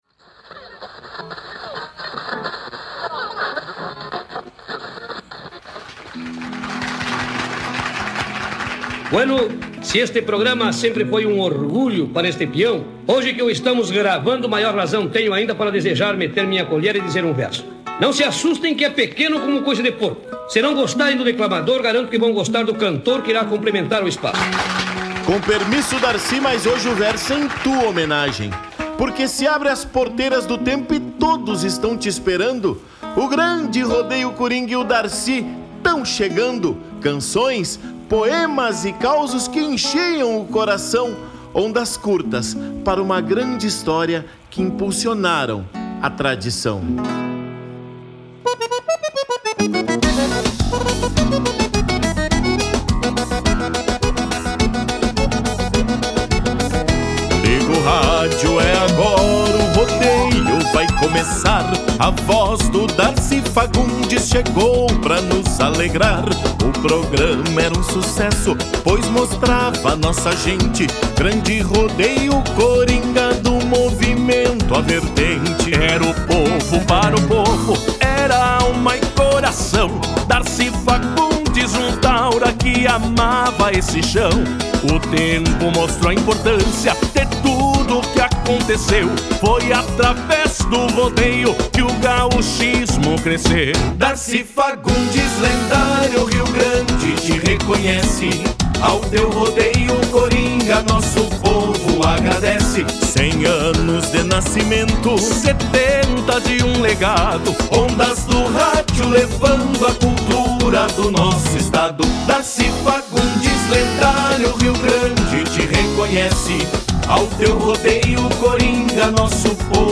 vocalista
acordeonista